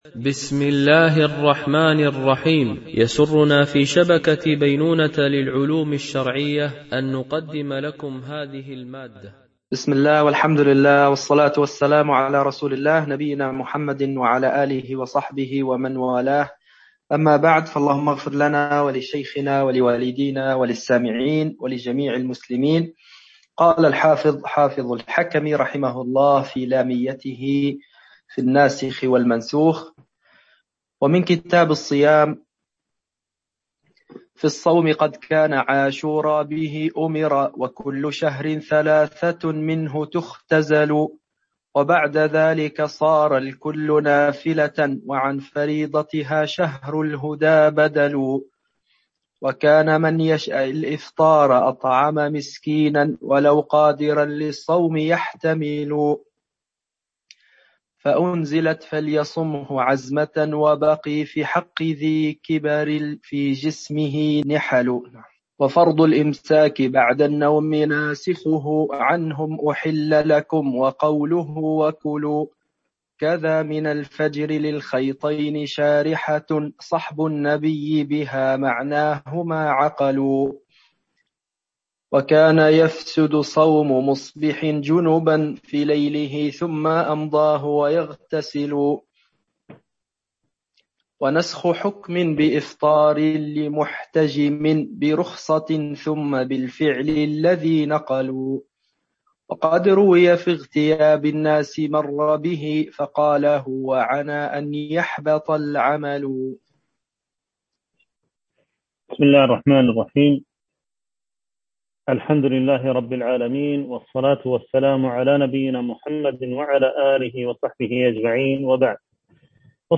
شرح المنظومة اللامية في الناسخ والمنسوخ - الدرس 15